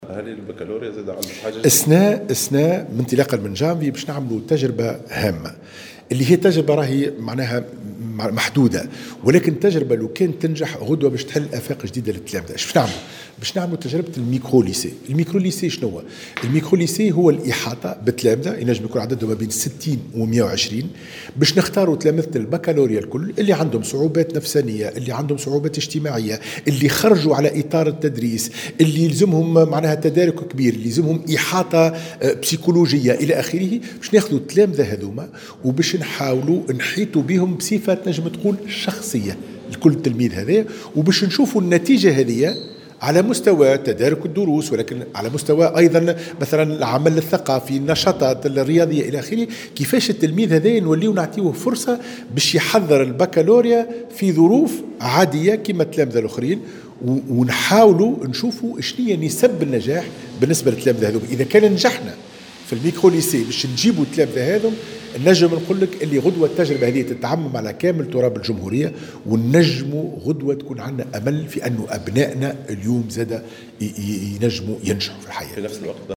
كشف وزير التربية حاتم بن سالم، في تصريح لمراسلة "الجوهرة اف أم" اليوم الاثنين عن اطلاق تجربة جديدة نموذجية لفائدة تلاميذ الباكالوريا بداية من شهر جانفي المقبل.